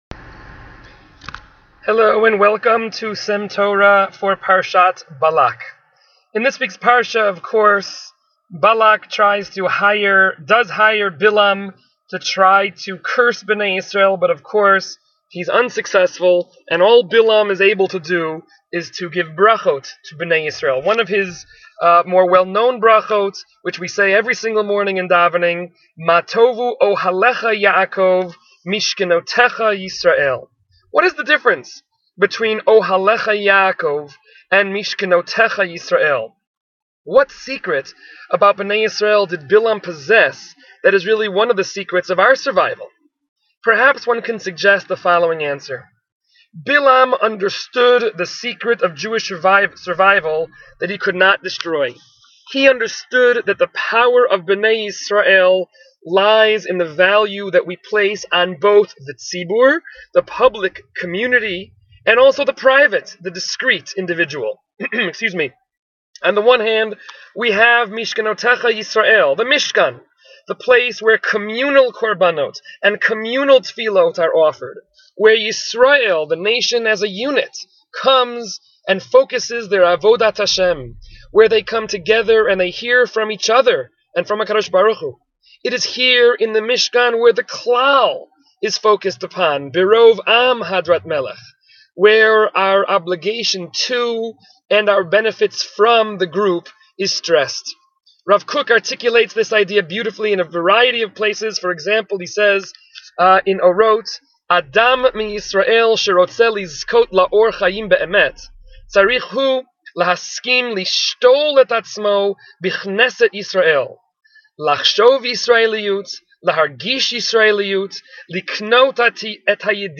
S.E.M. Torah is a series of brief divrei Torah delivered by various members of the faculty of Sha’alvim for Women.